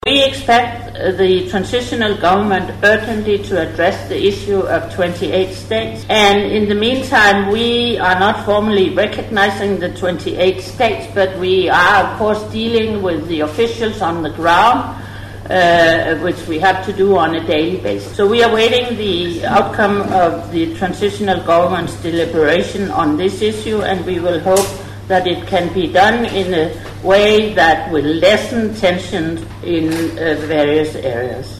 Ellen Margret Loej told a news conference on Wednesday that the transitional government should urgently resolve the issue of the states’ in a peaceful manner.